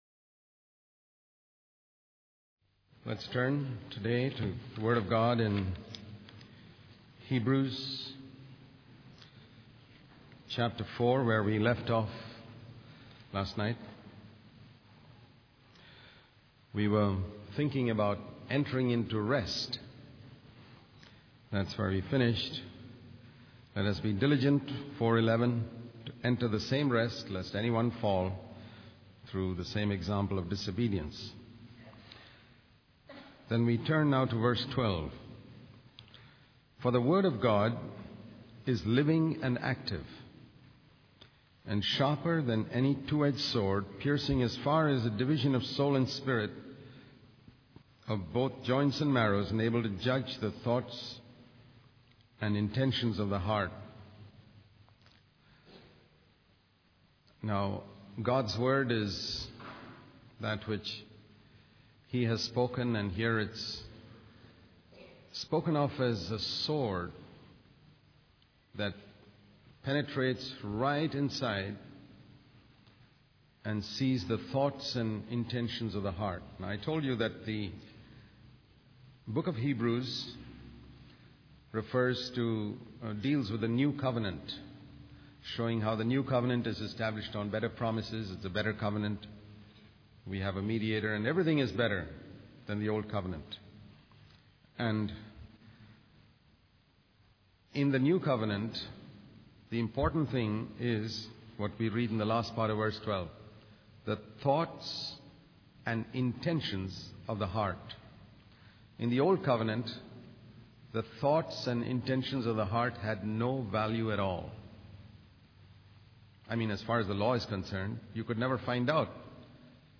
In this sermon on Hebrews chapter four, the preacher emphasizes the importance of entering into God's rest and warns against disobedience. The Word of God is described as a living and active sword that can discern the thoughts and intentions of the heart.